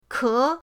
ke2.mp3